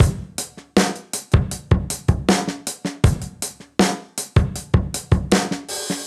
Index of /musicradar/sampled-funk-soul-samples/79bpm/Beats
SSF_DrumsProc1_79-04.wav